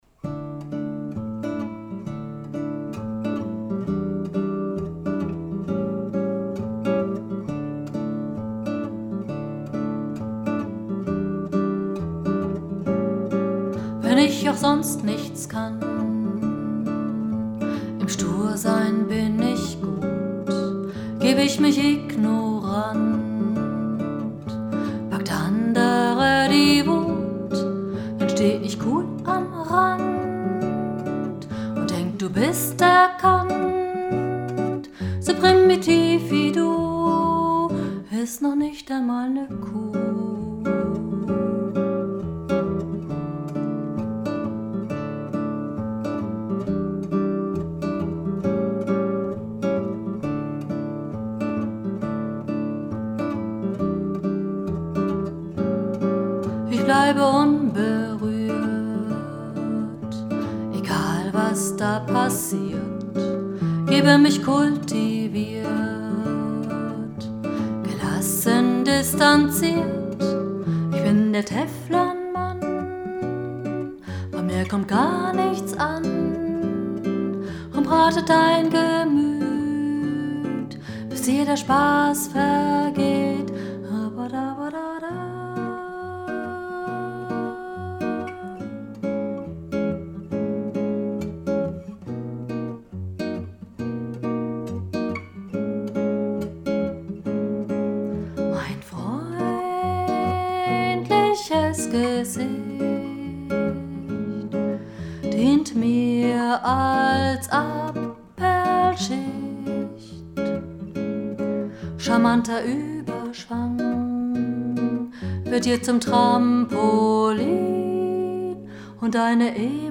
Kategorie: Lieder
Übungs- und Merkaufnahme, Teil 1, Juni 2012